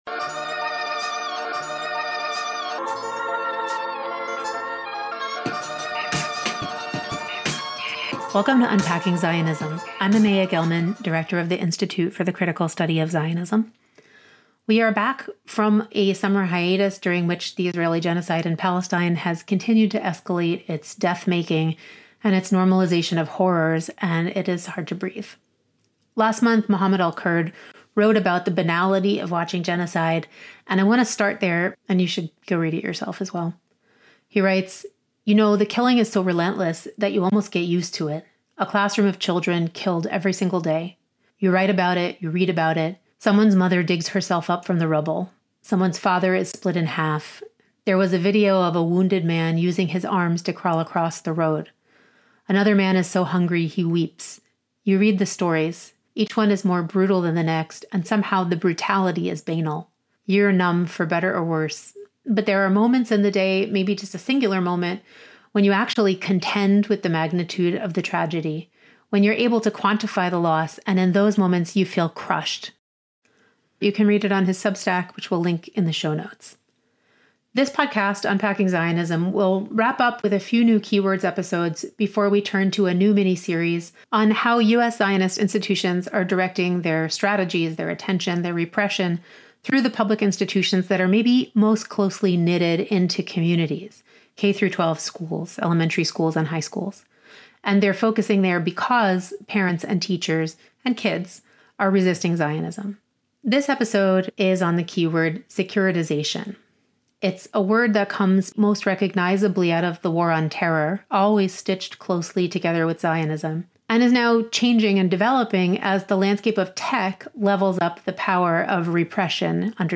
This interview was recorded in June 2025.